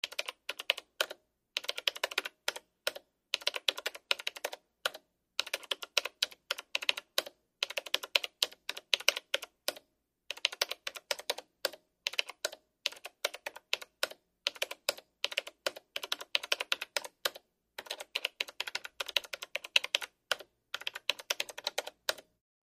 PcKeyboardMultiTap PE263004
PC Keyboard 4; Desktop Keyboard; Tap Delete Key, Then Spacebar, Close Perspective.